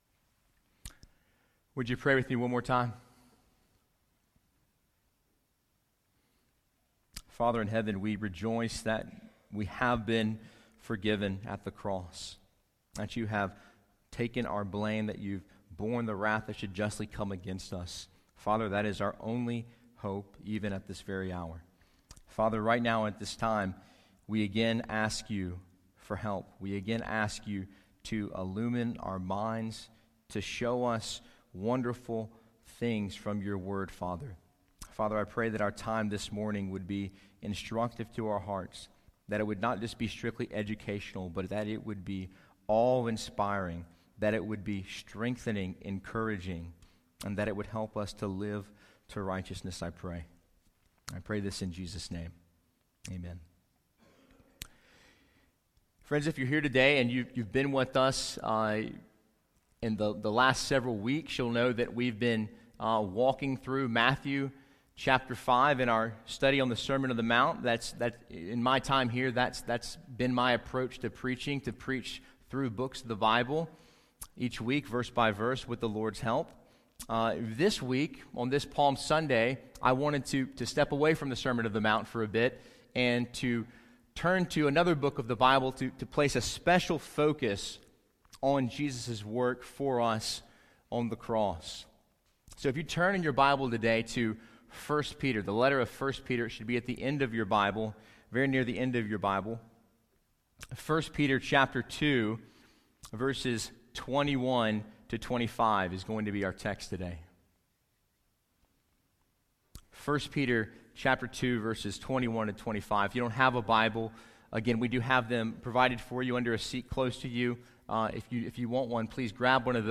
Sermon Audio 2018 March 25
Special message for Palm Sunday